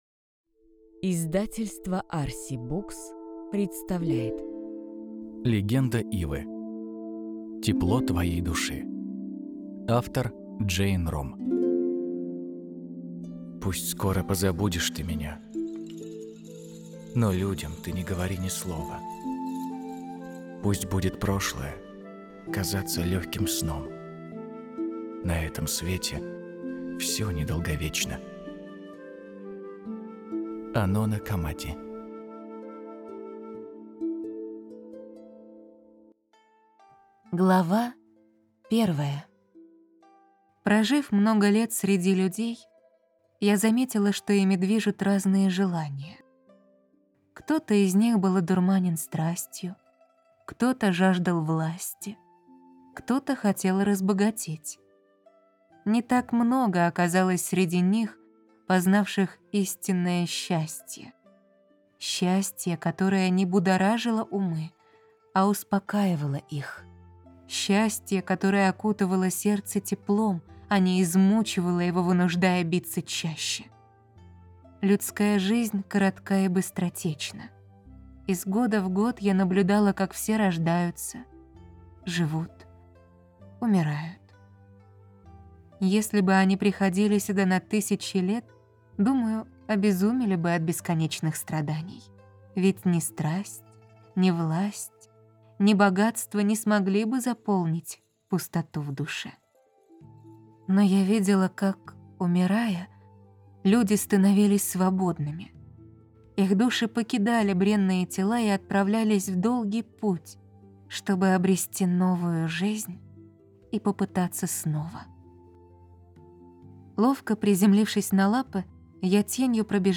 Аудиокниги Фэнтези